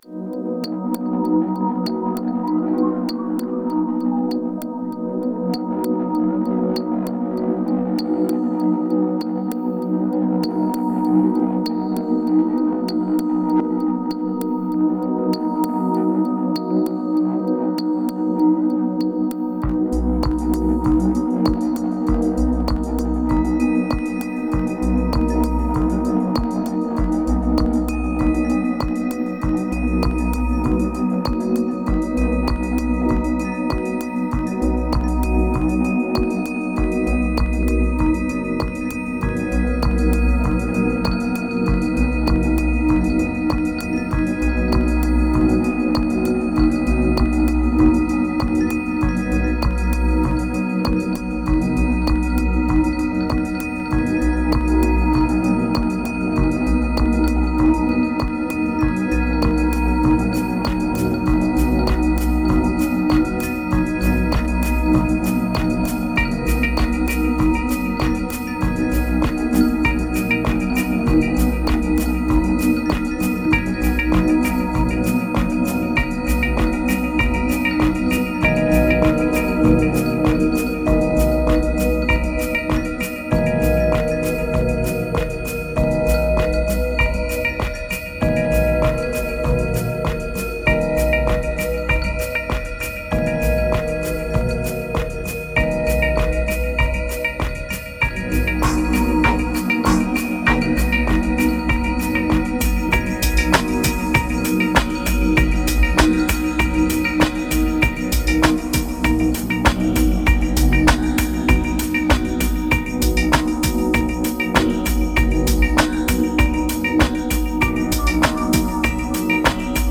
2061📈 - 33%🤔 - 98BPM🔊 - 2015-02-13📅 - 43🌟